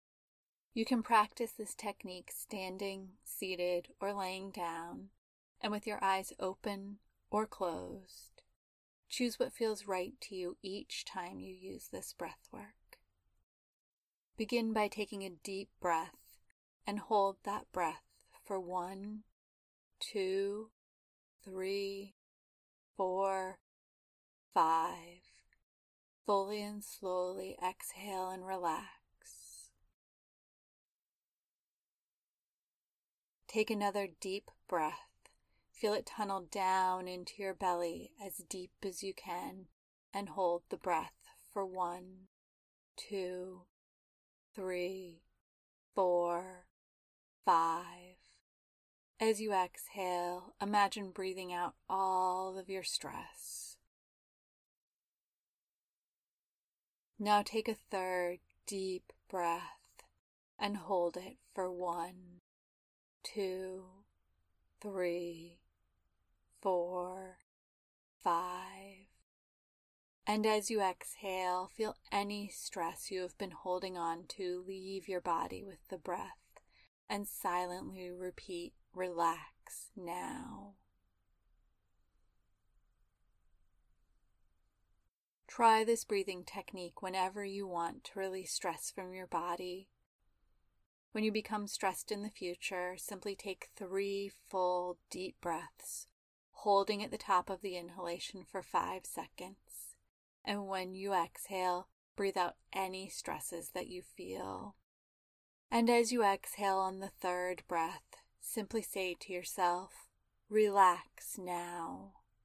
In addition to deep belly breaths, try this quick relaxation breath – it takes less than two minutes, and you might be surprised by the difference a few long, slow, intentional breaths can make!
Quick-Relaxation-Breath-Updated.mp3